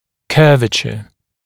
[‘kɜːvəʧə][‘кё:вэчэ]изгиб, кривизна